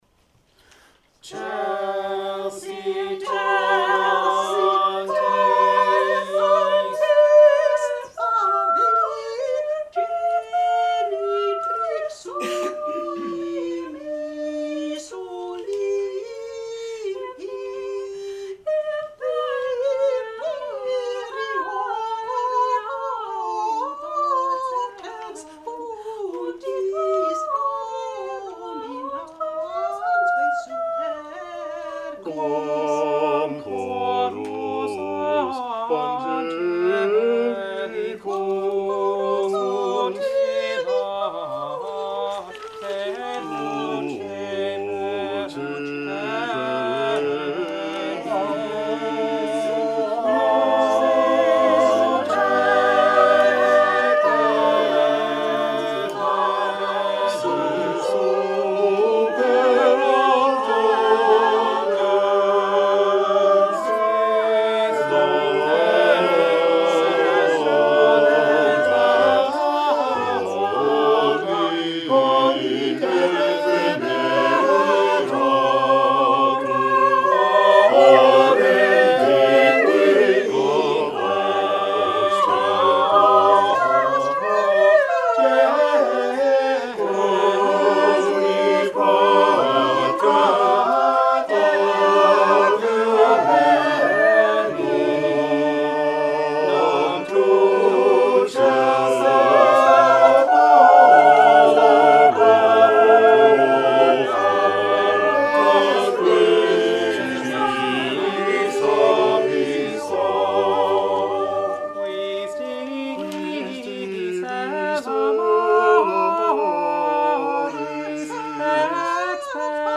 The Renaissance Street Singers' 46th-Anniversary Loft Concert, 2019